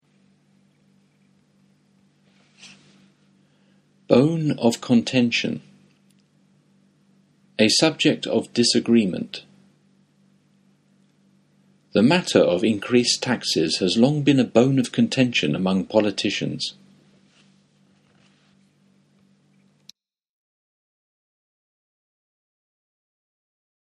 ネイティブによる発音は下記のリンクをクリックしてください。